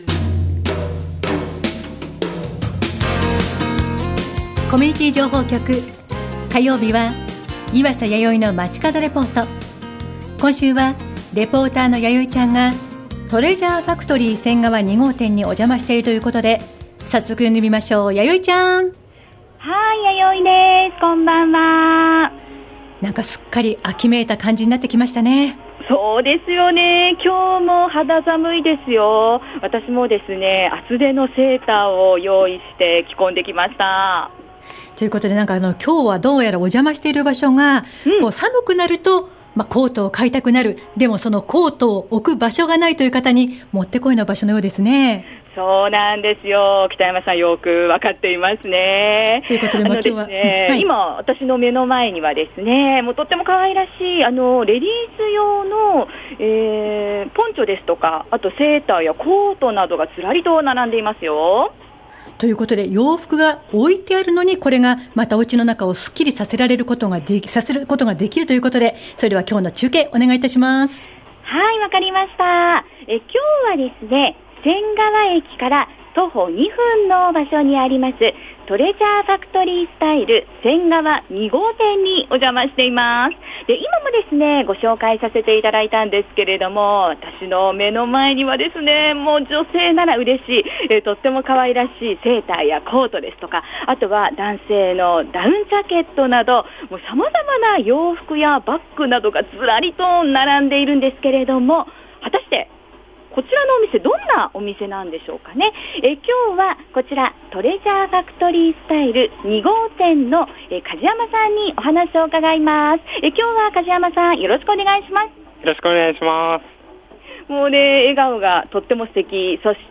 街角レポート
今週は仙川駅から徒歩２分の場所にある「トレジャーファクトリースタイル仙川２号店」におじゃましました～！